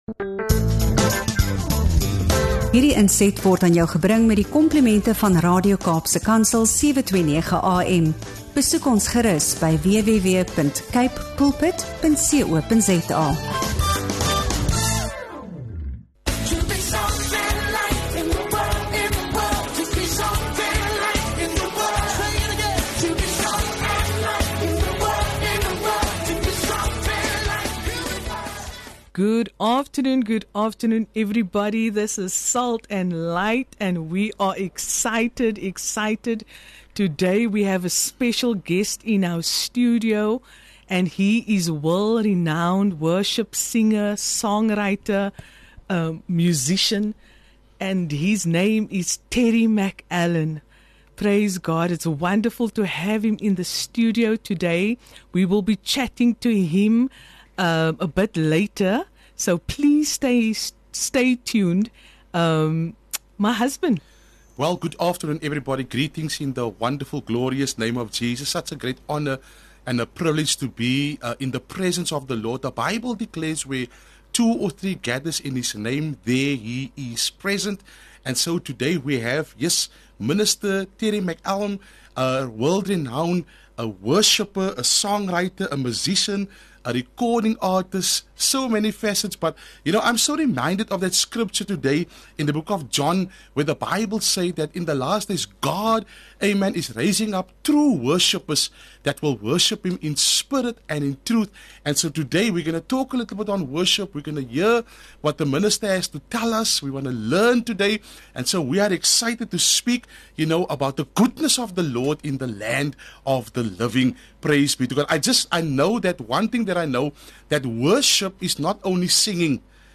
Whether you’re a worship leader, musician, or simply hungry for God’s presence, this inspiring conversation will refresh your spirit and reignite your passion for Jesus.